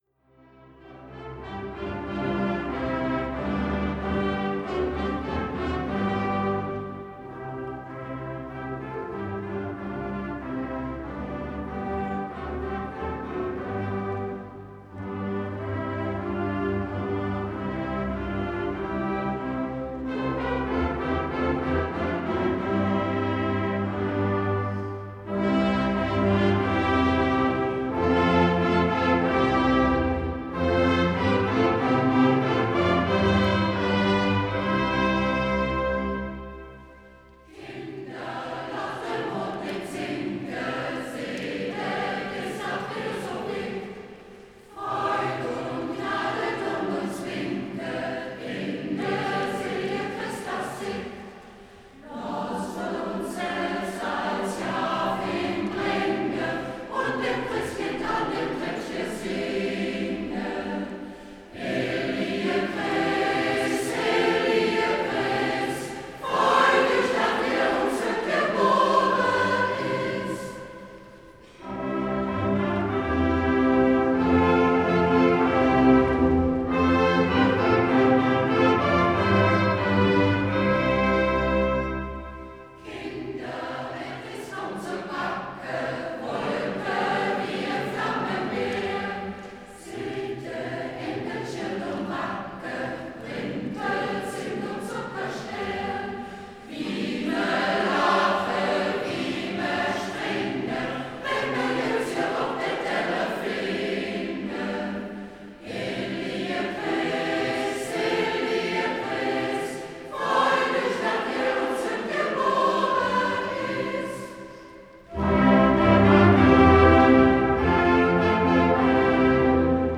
Aachener Weihnachtlied; Tonaufnahme Kirchenchor St. Joseph Thb.